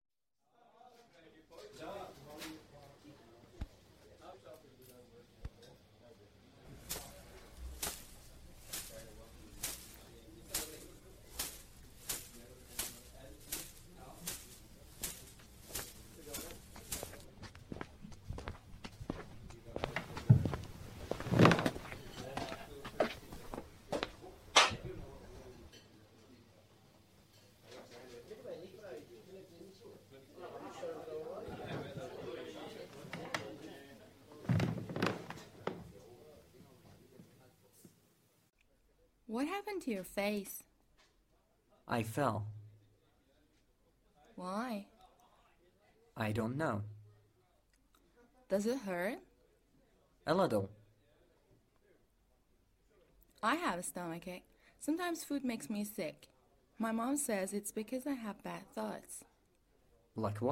کتاب صوتی انگلیسی Gruesome Playground Injuries | مرجع دانلود زبان